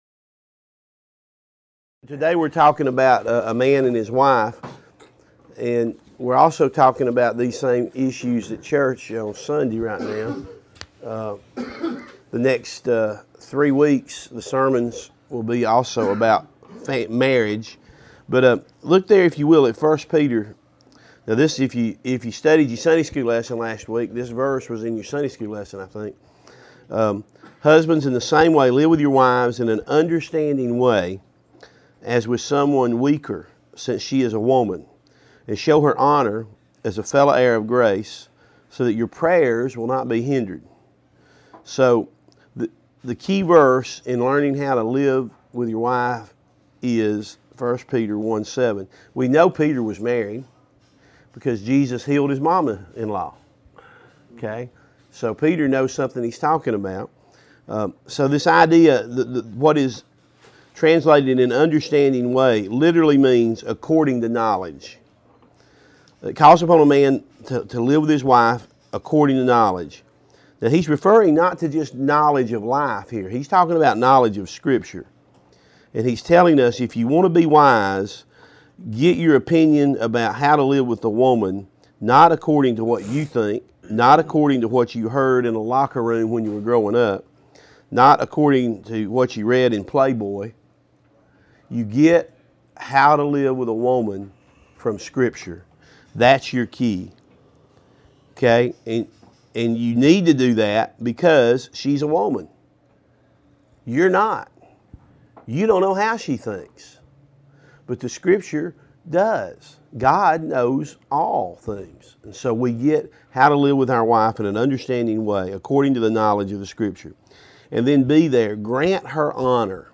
Join us for “The Huddle” men’s Bible study today, as we look at the marriage relationship between man and wife.